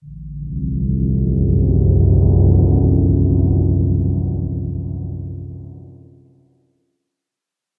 sounds / ambient / cave / cave3.mp3
cave3.mp3